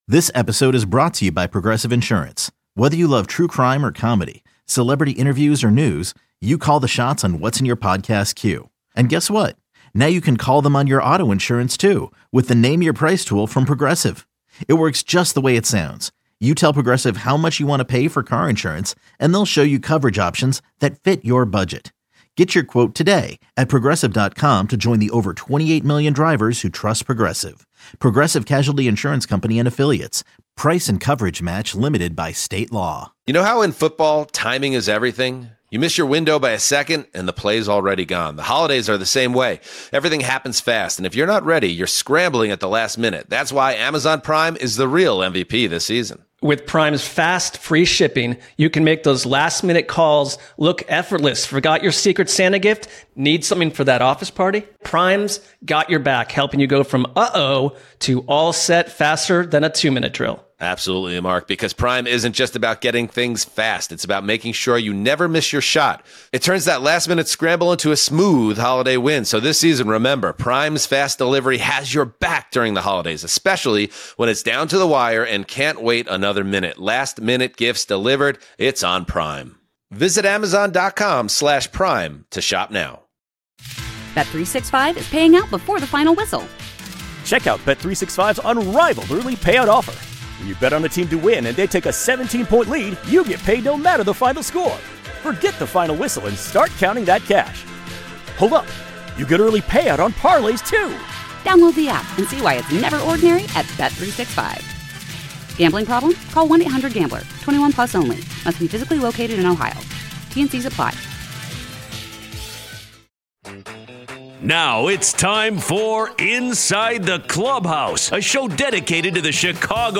White Sox land the No. 1 overall pick in 2026, Pat Murphy interview (Hour 1)